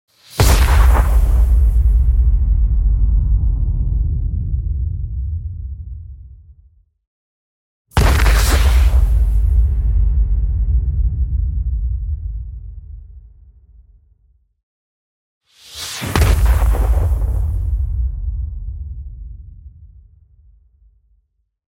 دانلود آهنگ رعدو برق 29 از افکت صوتی طبیعت و محیط
دانلود صدای رعدو برق 29 از ساعد نیوز با لینک مستقیم و کیفیت بالا
جلوه های صوتی